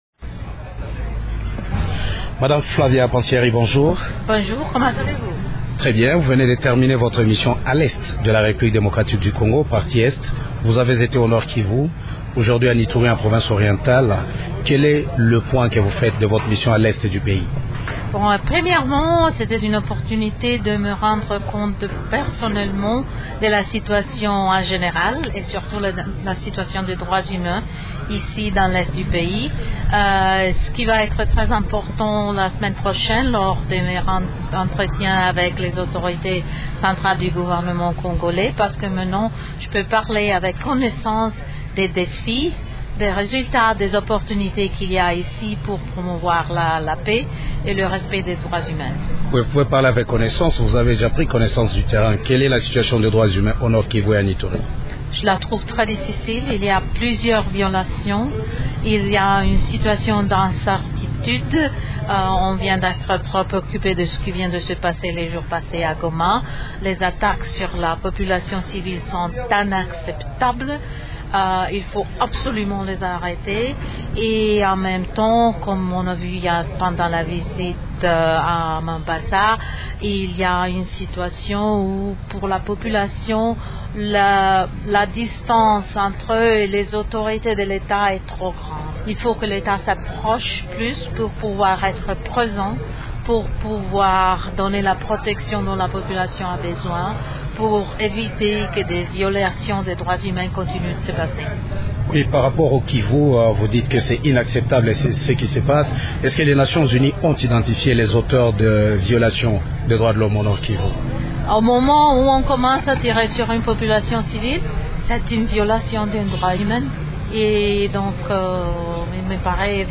Elle qualifie d’inacceptables les attaques contre les populations civiles qui s’y commettent. Flavia Pansieri est l’invitée de Radio Okapi.